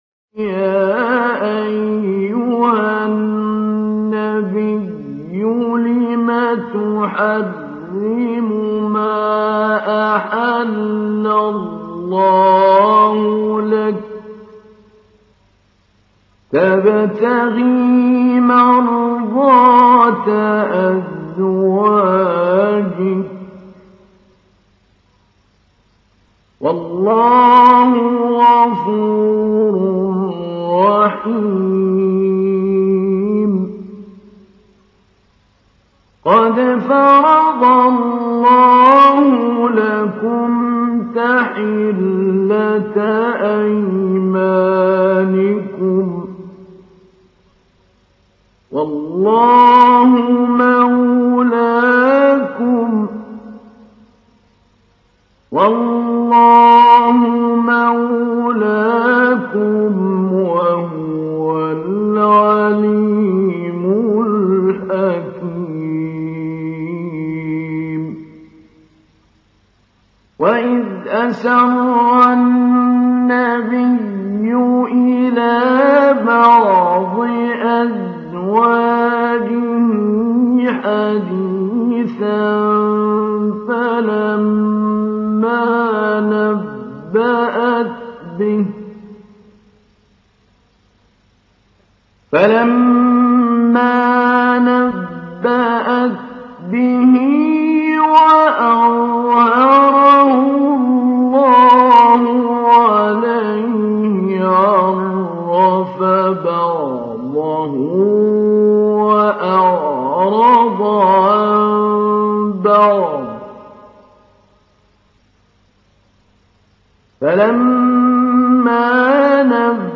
تحميل : 66. سورة التحريم / القارئ محمود علي البنا / القرآن الكريم / موقع يا حسين